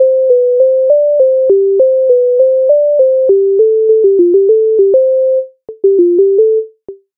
MIDI файл завантажено в тональності C-dur
Ой не рости кропе Українська народна пісня з Повного зібрання творів М.Лисенка, т.19
Ukrainska_narodna_pisnia_Oj_ne_rosty_krope.mp3